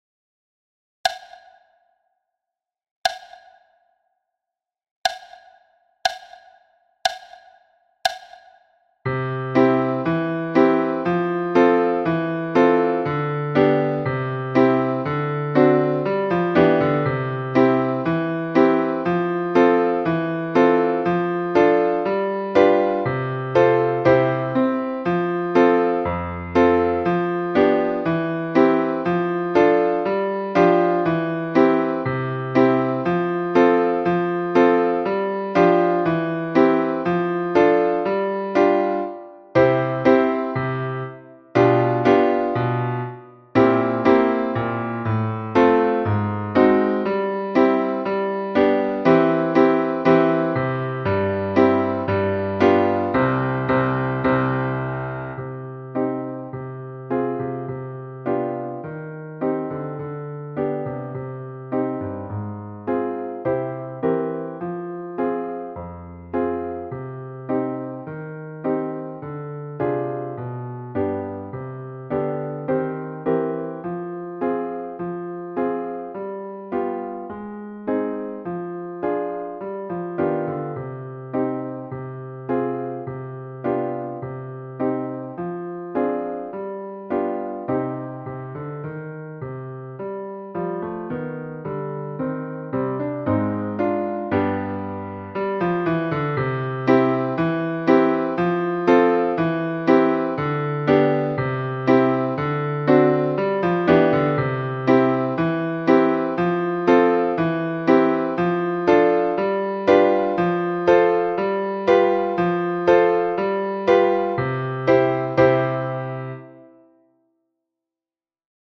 Après la pluie – piano à 60 bpm